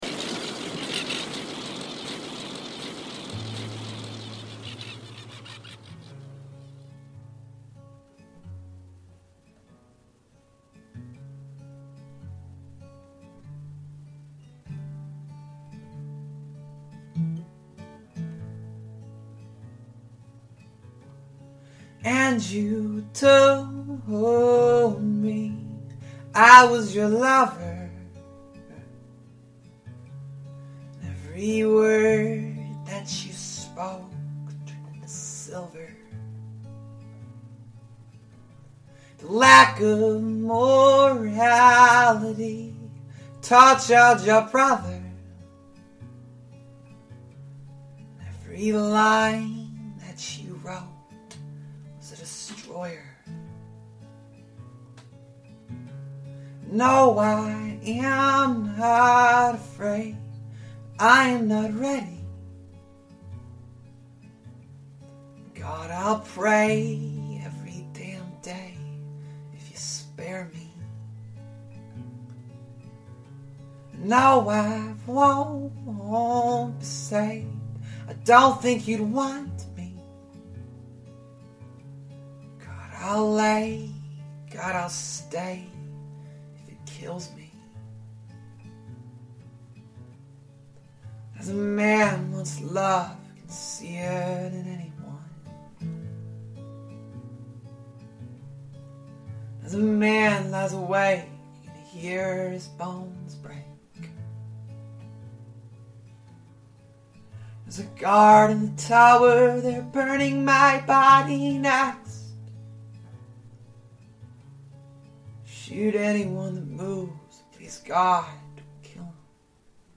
dark Americana tinged singer songwriter projects